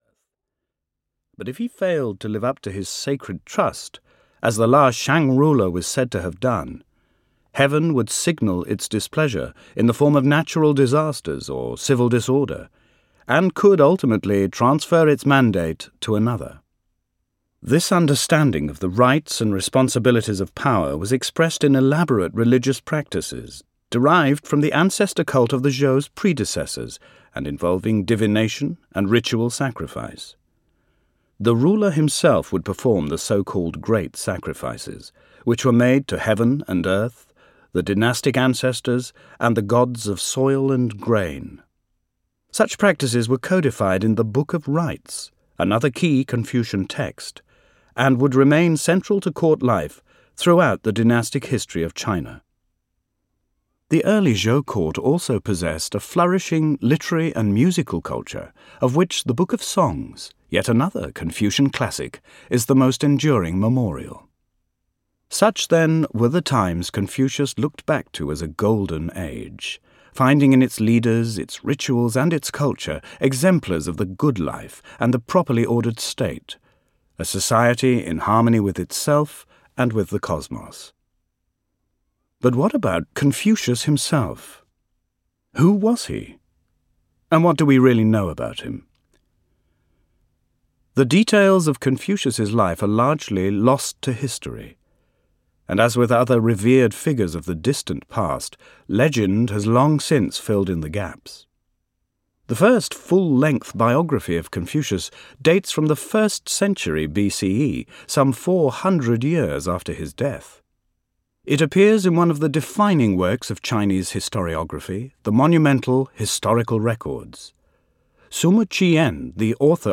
Confucius – In a Nutshell (EN) audiokniha
Ukázka z knihy